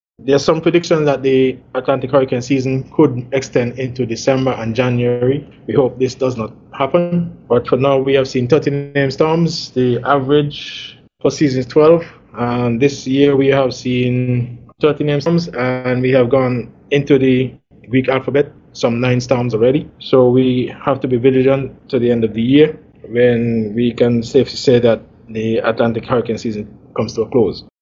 Director of the Nevis Disaster Management Department, Mr. Brian Dyer.
He was speaking at the latest EOC COVID-19 Taskforce Briefing on Monday, November 23rd, 2020.
In light of the busy season, Mr. Dyer gave this warning: